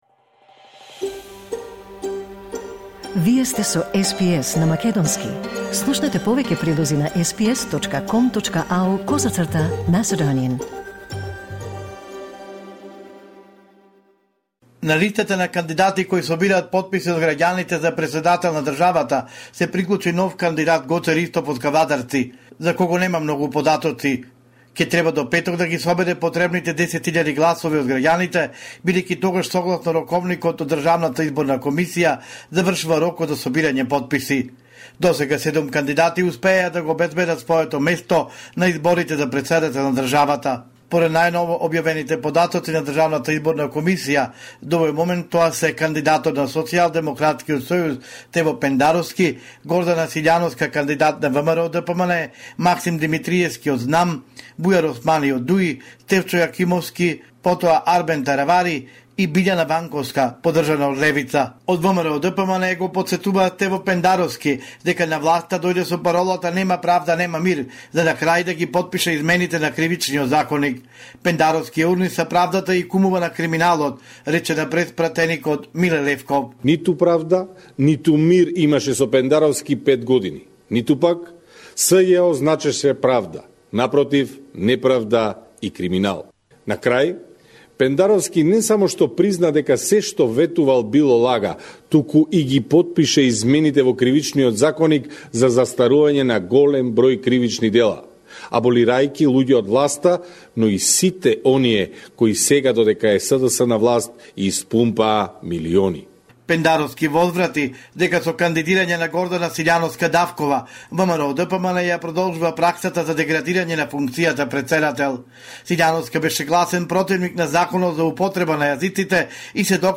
Homeland Report in Macedonian 6 March 2024